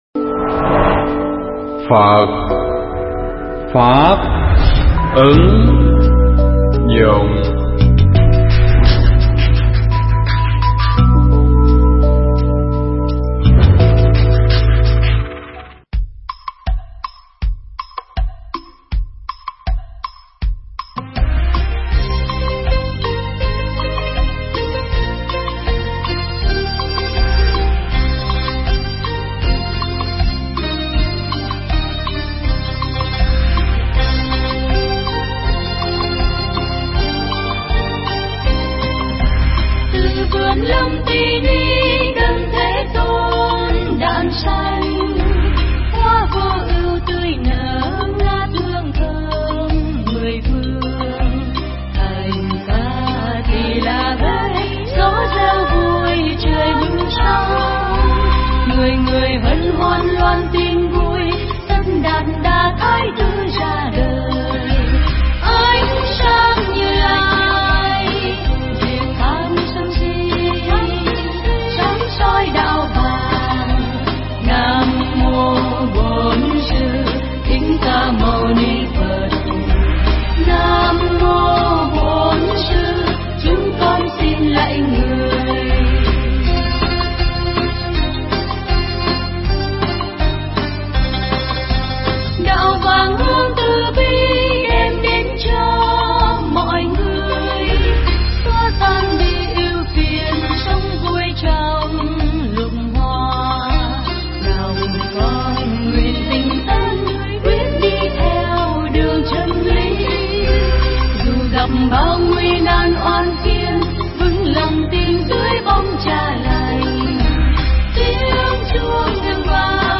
Nghe Mp3 thuyết pháp